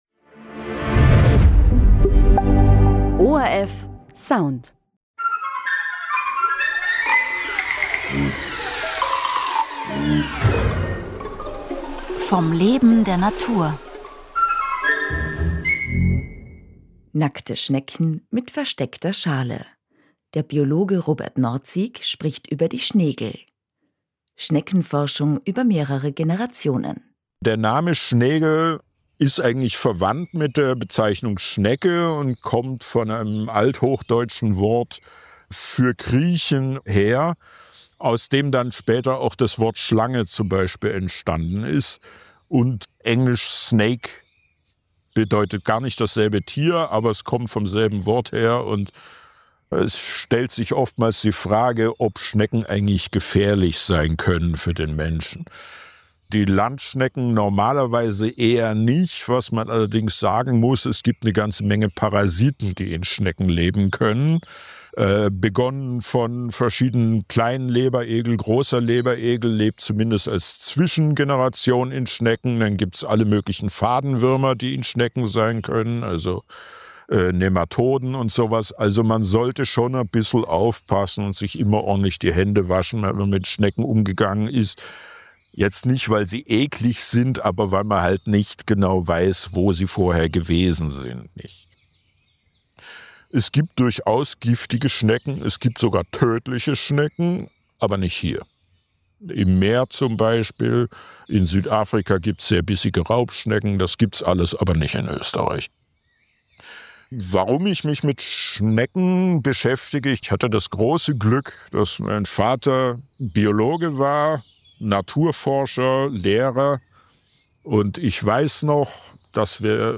Radiointerview im ORF1 Radio